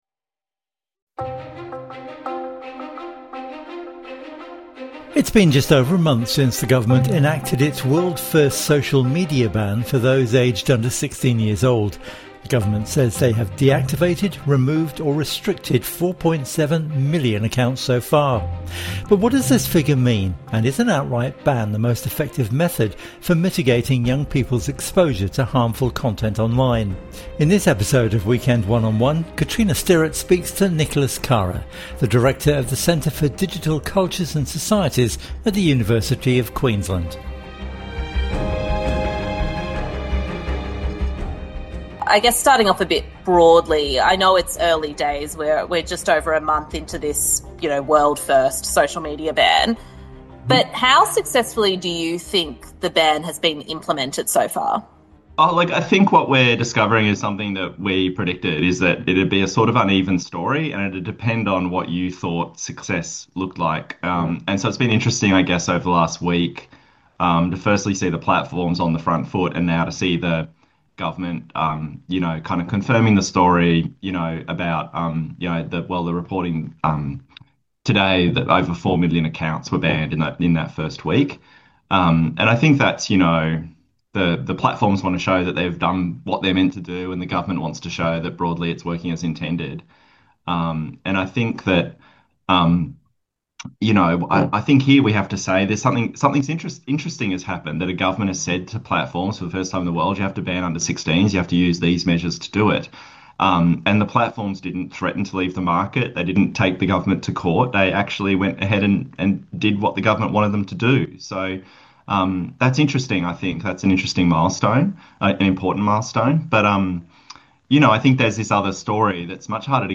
INTERVIEW: How is the social media ban working and is it an effective measure?